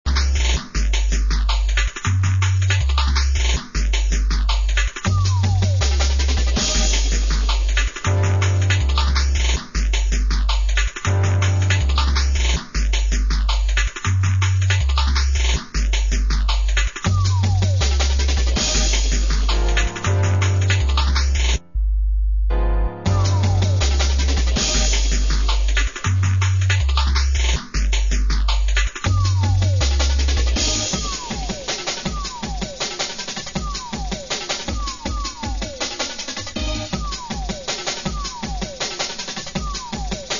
Tercera maqueta con estilo bailable y ritmos rápidos.
Jungle